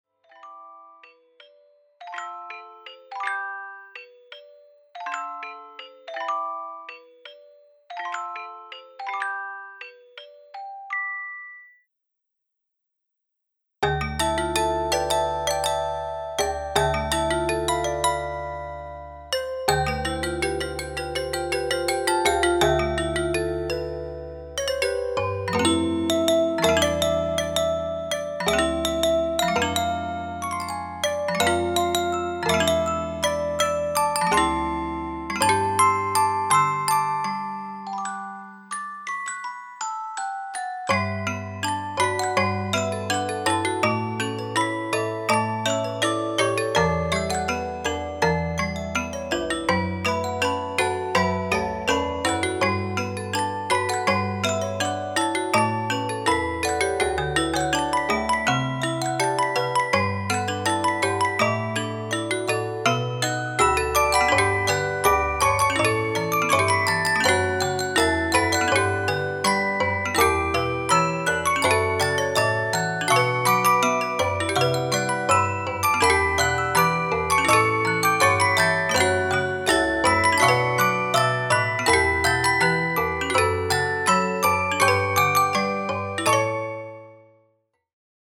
※再生中にノイズや歪みのように聴こえる箇所がありますが、